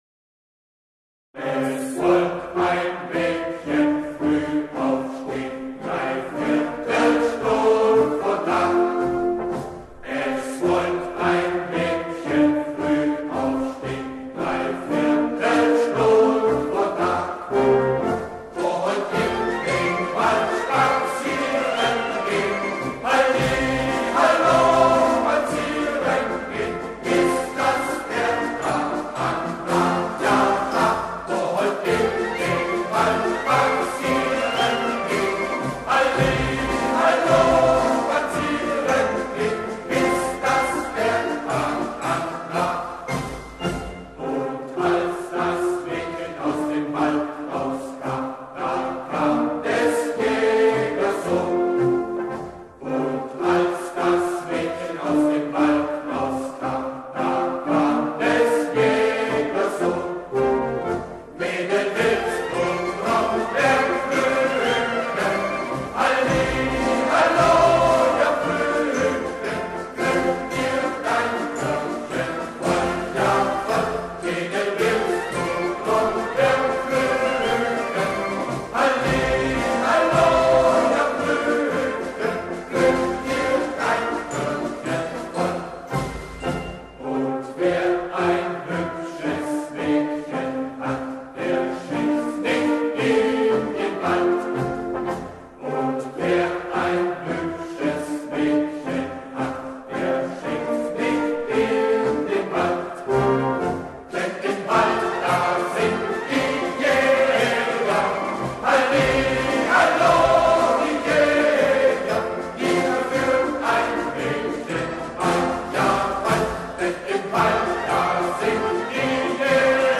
10 German Marching Songs